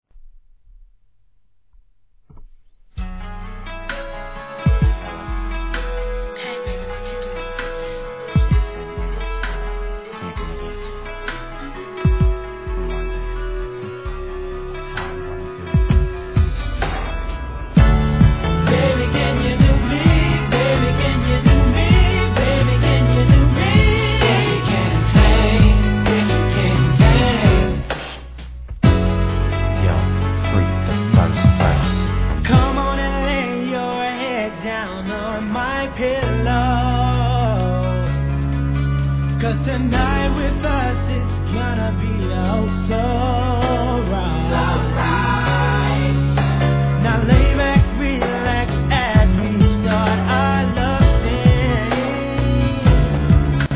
本当に実力があるコーラスワークはコアなR&Bファンの肥えた耳も納得させる出来栄え♪アカペラで聞いてもいいですもんね。